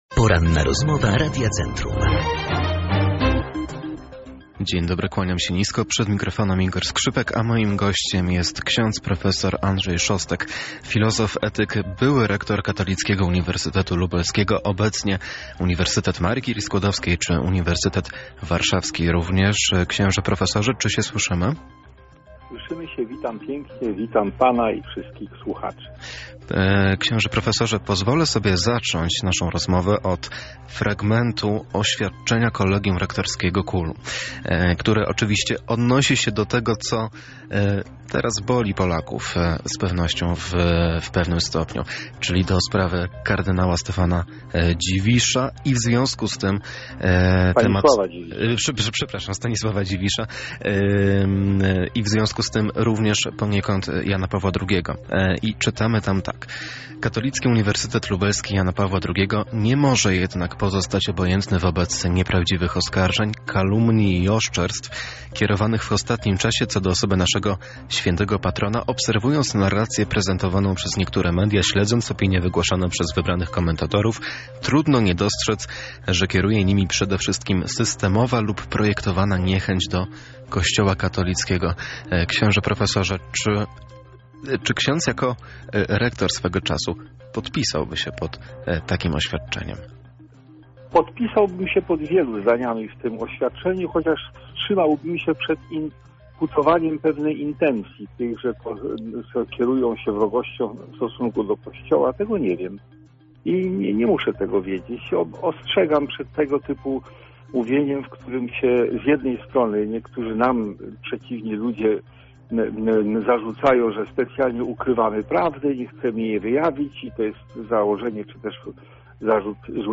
Pełną rozmowę